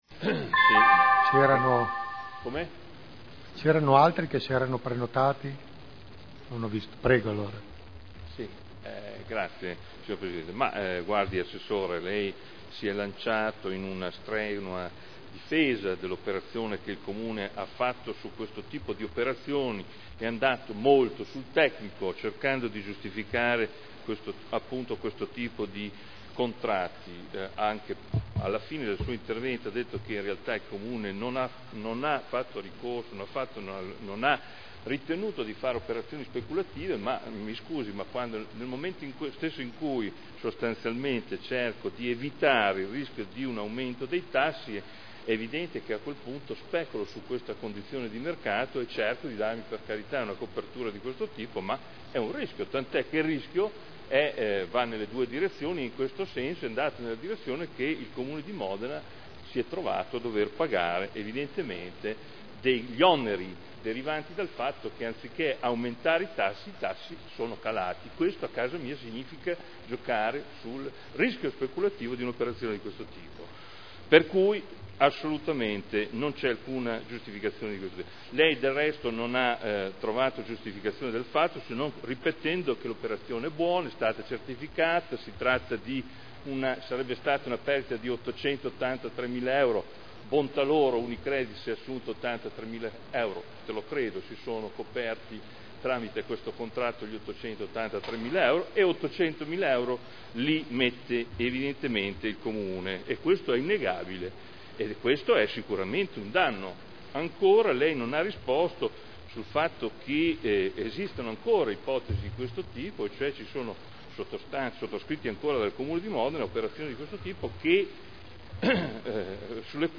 Seduta del 24/01/2011. dibattito su Interpellanza dei consiglieri Morandi e Pellacani (PdL) avente per oggetto: “Il Comune di Modena “chiude” in sordina una parte dei “derivati” con una perdita di € 800.000,00.